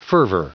Prononciation du mot fervor en anglais (fichier audio)